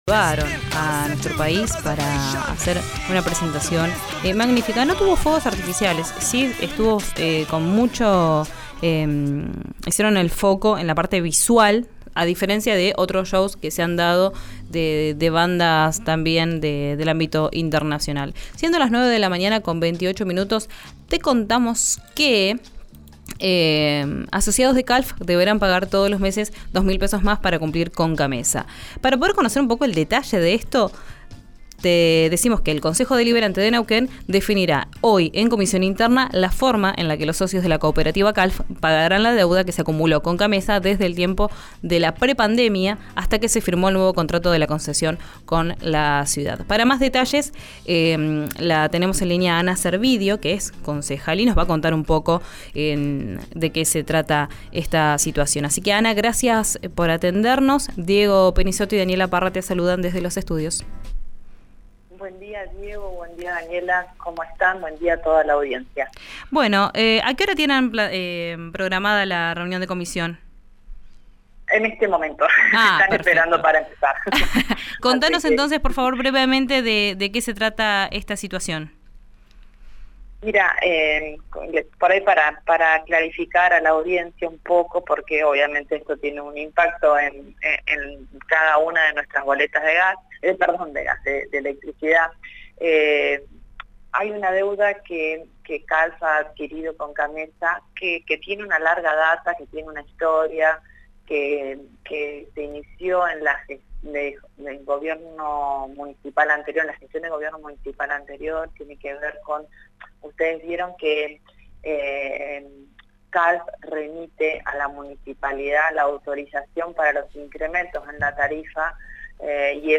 Escuchá a la concelaja Ana Servidio en RÍO NEGRO RADIO: